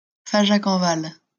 Fajac-en-Val (French pronunciation: [faʒak ɑ̃ val]